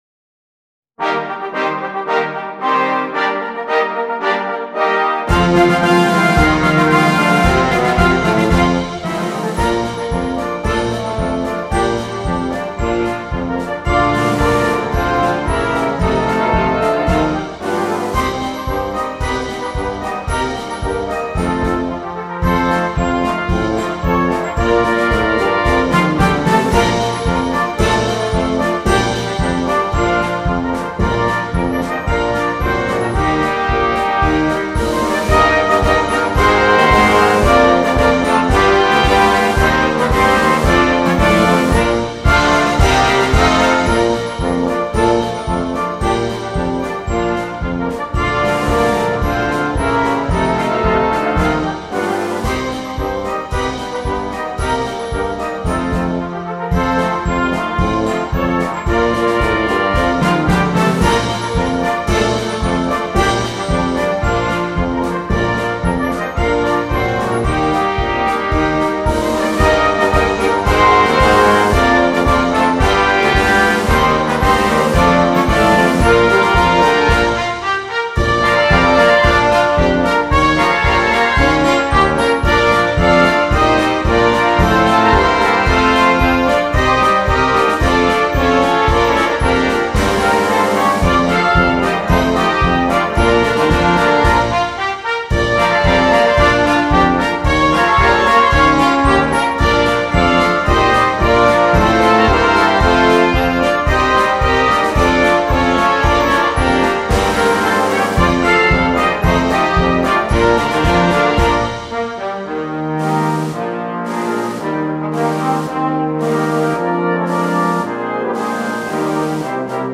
Besetzung: Brass Band